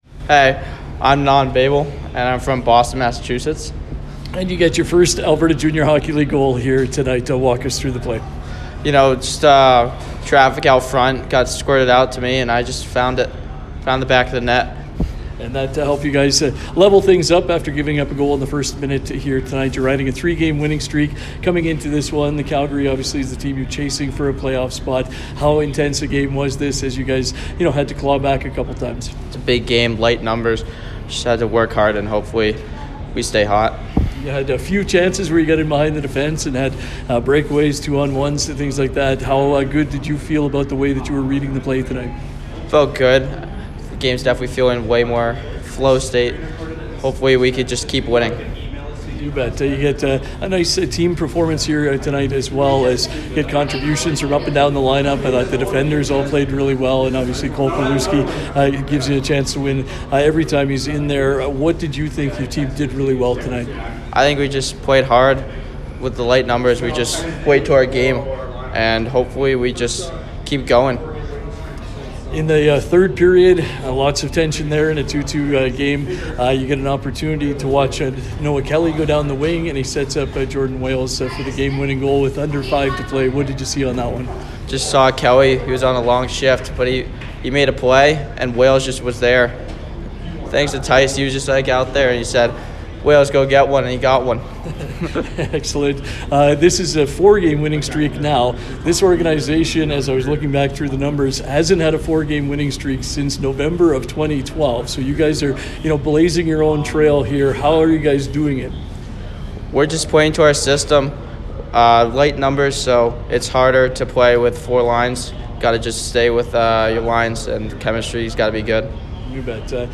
post-win conversation